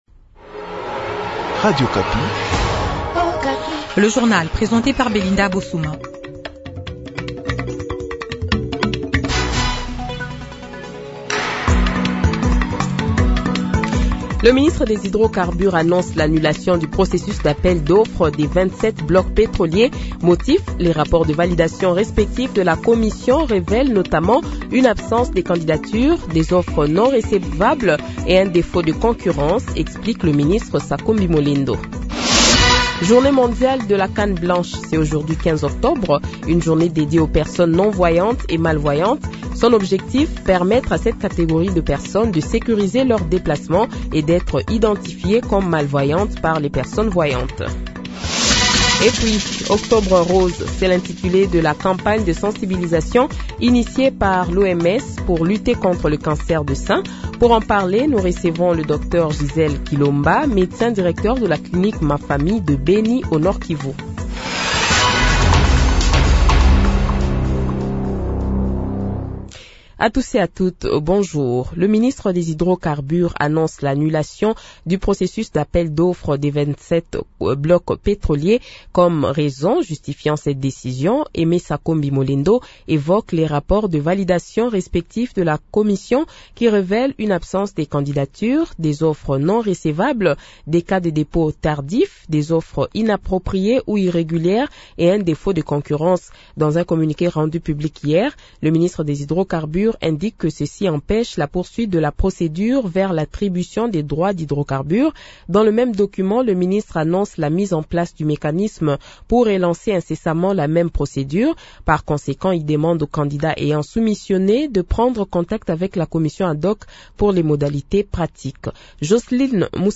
Journal Francais Matin
Le Journal de 8h, 15 Octobre 2024 :